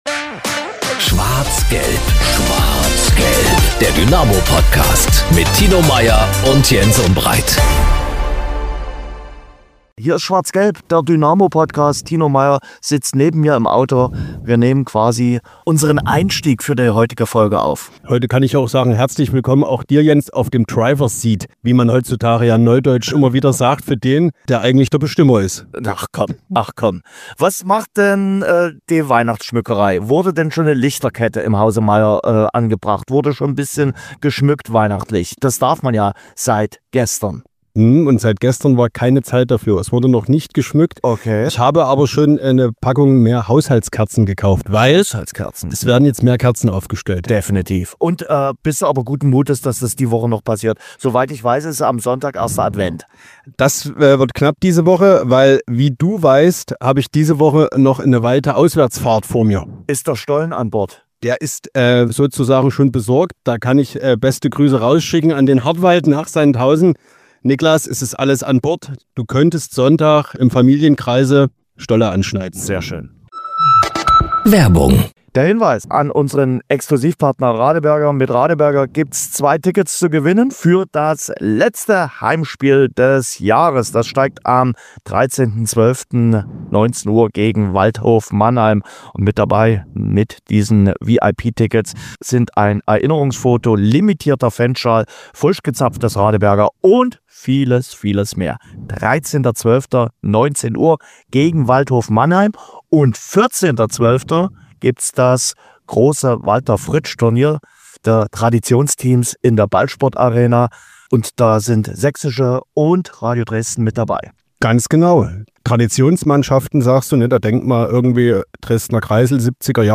#096 Punkt gegen Saarbrücken, Sandhausen-Preview, Interview mit Michael Rösch ~ SCHWARZ GELB - Der Dynamo-Podcast Podcast